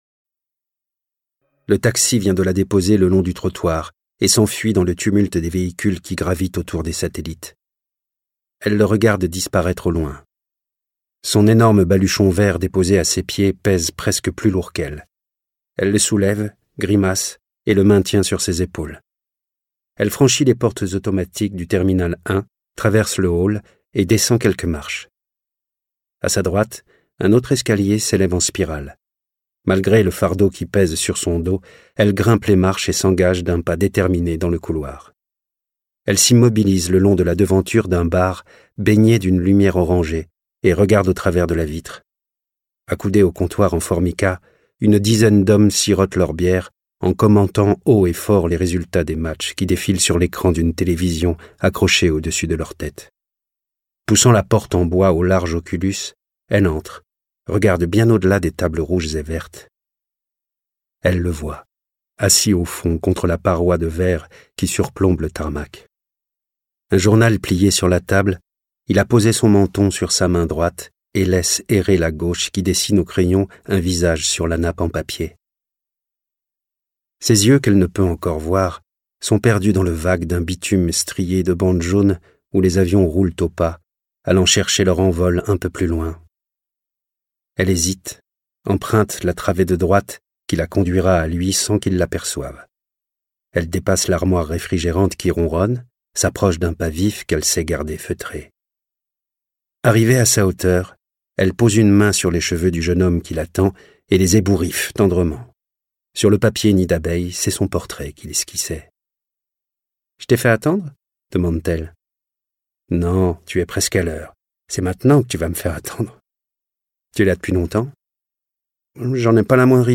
(Re)Découvrez Où es-tu ?, une histoire d'amour bouleversante et culte de Marc Levy, portée par un trio de comédiens et un habillage sonore immersif !